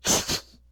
hitwall.ogg